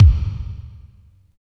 31.03 KICK.wav